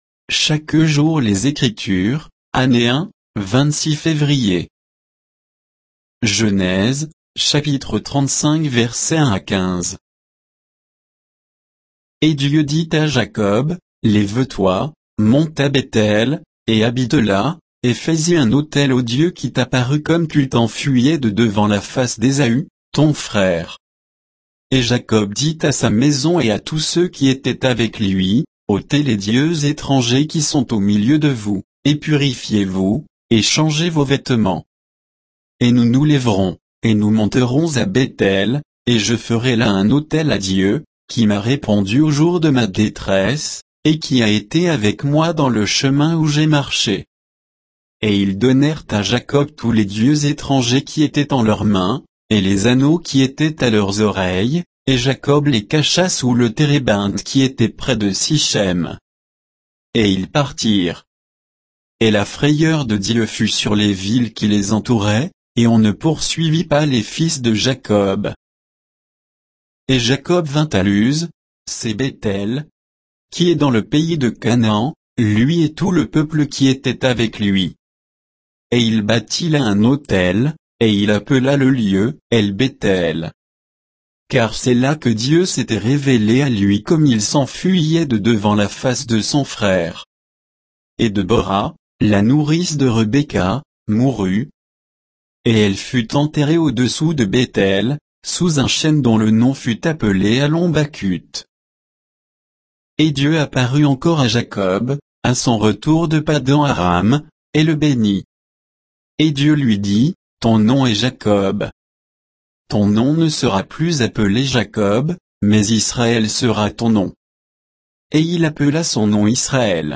Méditation quoditienne de Chaque jour les Écritures sur Genèse 35, 1 à 15